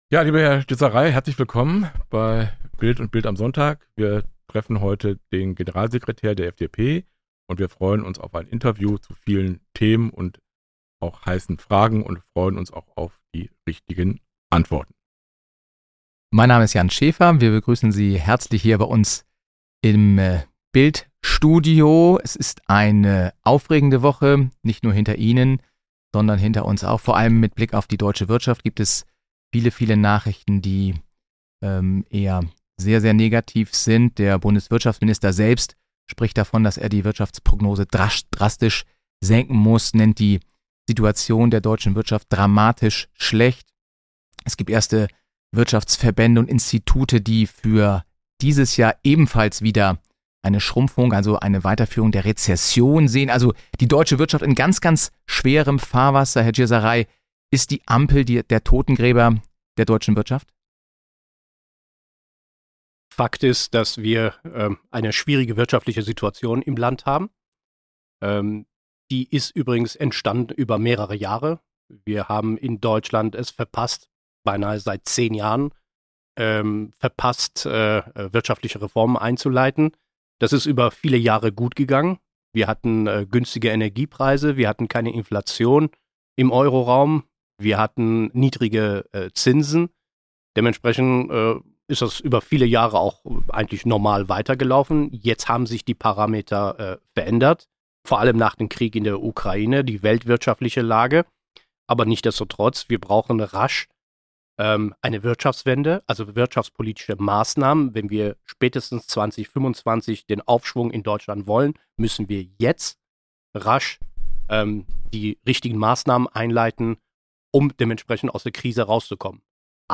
BILD traf FDP-Generalsekretär Bijan Djir-Sarai (47), sprach mit ihm über ein mögliches Ende der Koalition und die schwierige Lage der Liberalen.